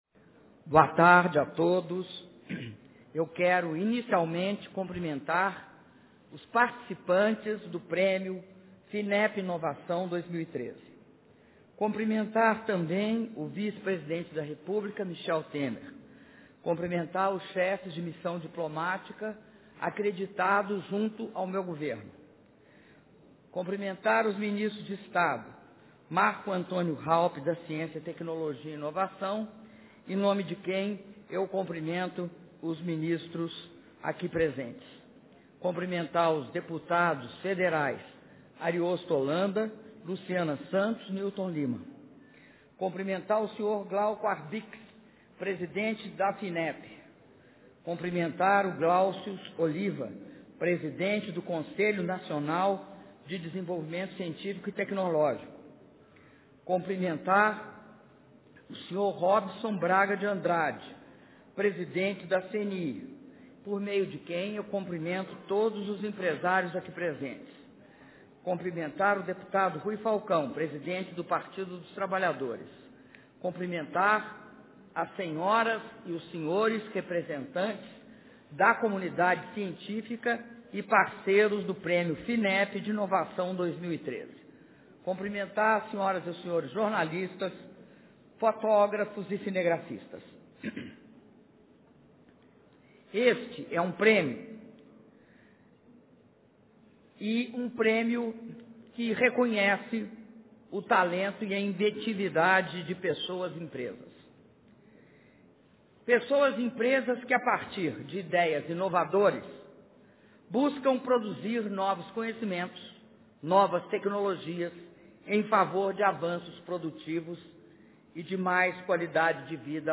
Áudio do discurso da Presidenta da República, Dilma Rousseff, na cerimônia de entrega da 16ª edição do Prêmio Finep de Inovação